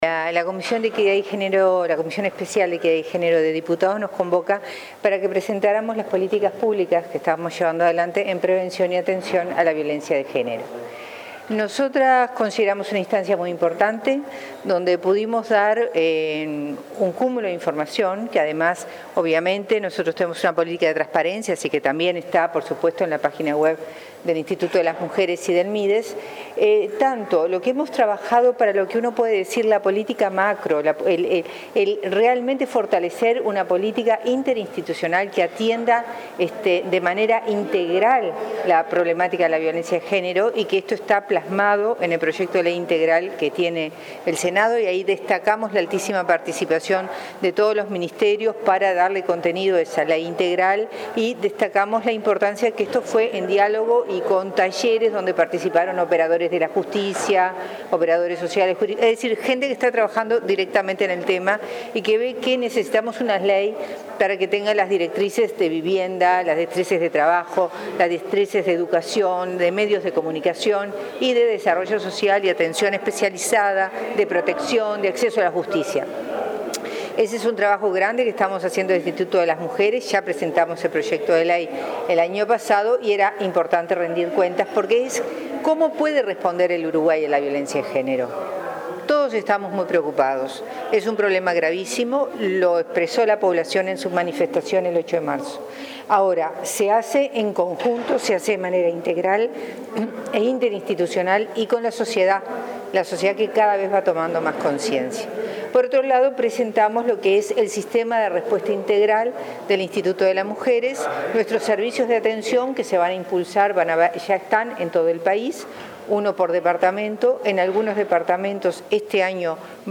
La responsable de Inmujeres, Mariela Mazzotti, concurrió este jueves a la Comisión de Género de la Cámara de Diputados, donde habló de las políticas de atención y prevención de la violencia de género. Mazzotti presentó el sistema de respuesta integral que se instalará en todo el país, que incorpora atención psicológica y jurídica y acompañamiento del Ministerio del Interior a quienes están en el programa de tobilleras.